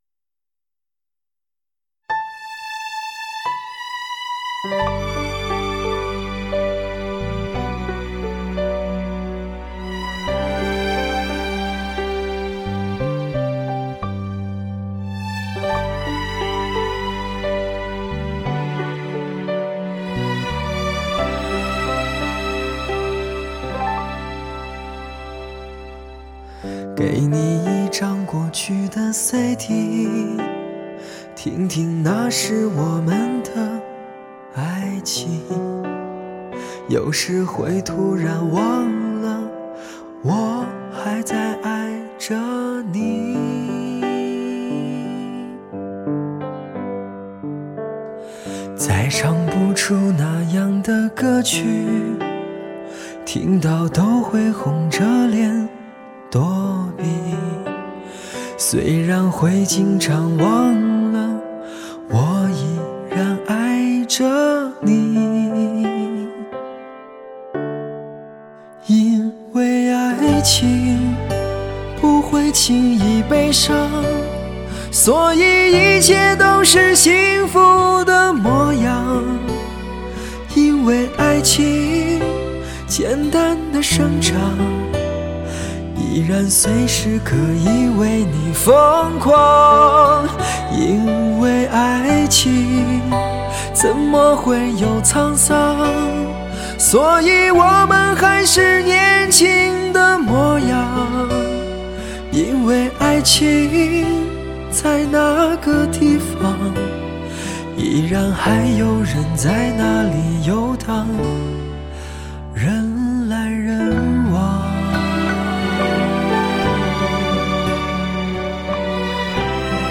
极富磁性的HIFI男声 首首发自肺腑 完美诠释男人内心世界偶然间的本色真情流露
真声假声高音低音众多动情的男声，时而高亢悠扬，时而低沉醇厚，时而锋芒如利刃，时而温暖如春风……